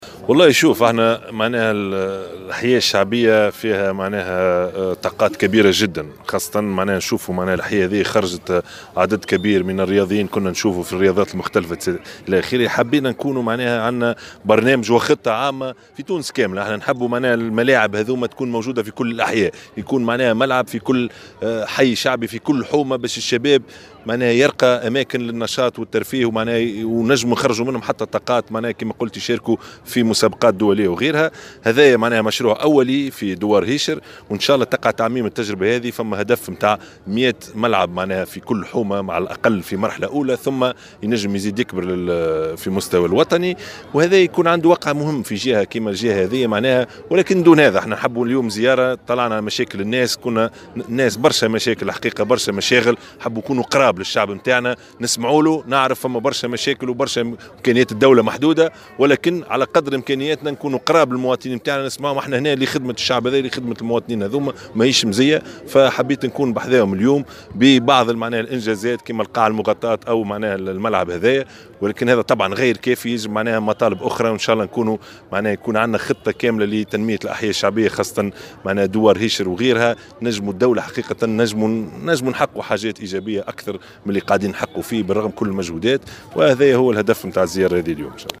قال رئيس الحكومة يوسف الشاهد اليوم الأربعاء 5 ديسمبر 2018 على هامش اشرافه على افتتاح قاعة رياضية بدوار هيشر إن الحكومة وضعت خطة لإحداث ملعب في كل حي شعبي لأن الأحياء الشعبية الشعبية تزخر بالطاقات على حد قوله.